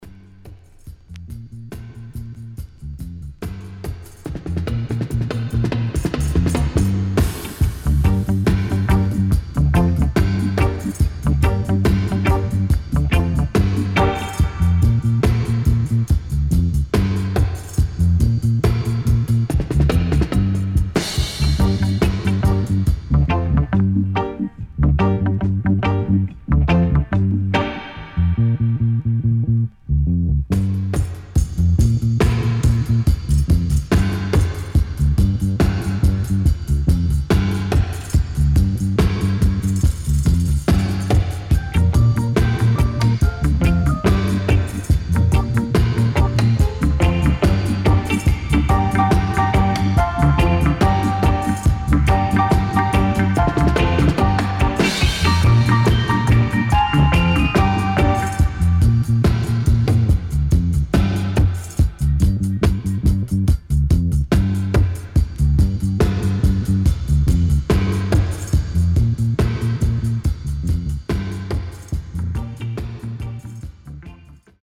HOME > DISCO45 [VINTAGE]  >  KILLER & DEEP
SIDE A:少しチリノイズ入りますが良好です。